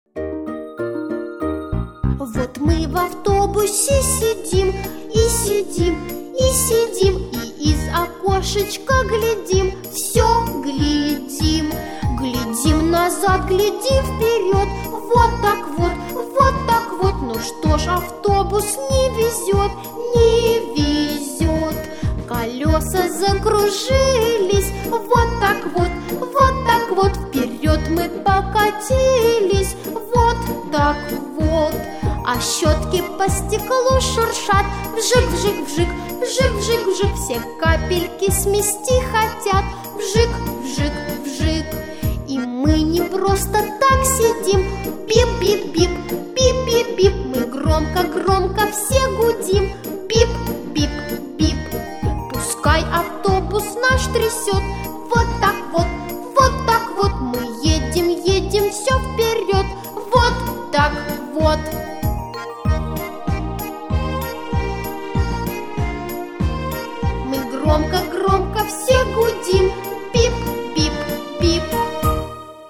6.Физ.минутка.
Проводится музыкальная физ.минутка "Автобус"